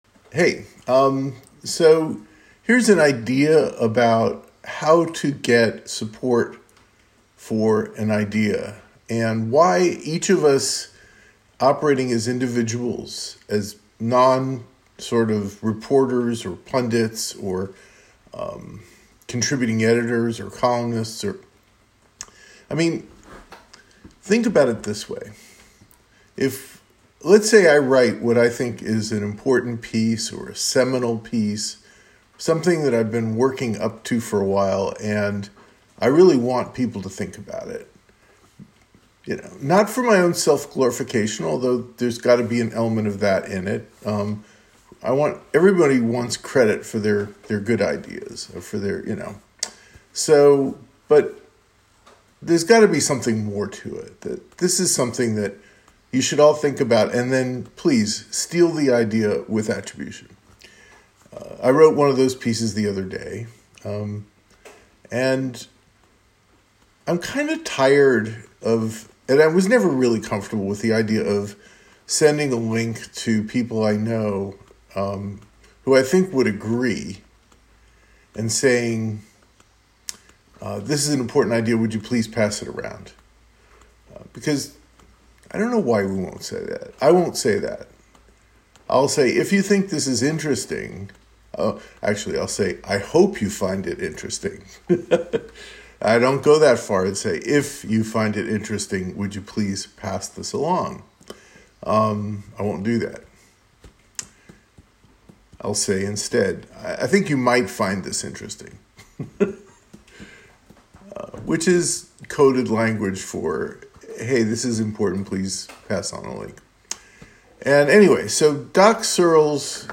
Rambly.